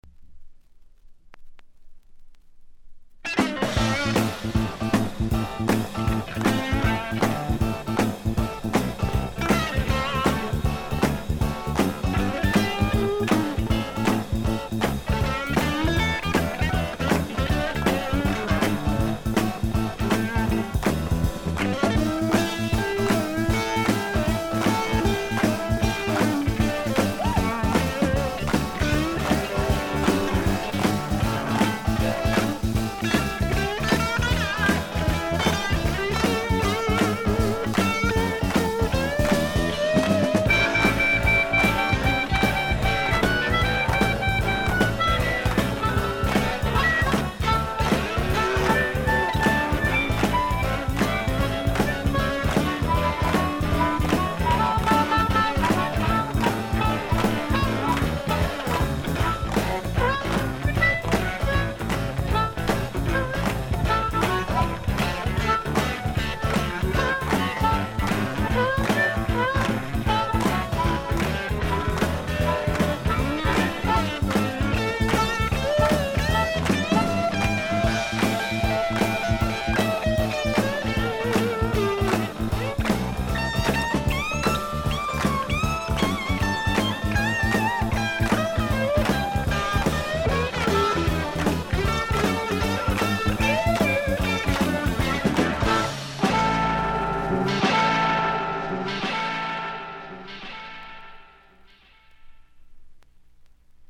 ほとんどノイズ感無し。
よりファンキーに、よりダーティーにきめていて文句無し！
試聴曲は現品からの取り込み音源です。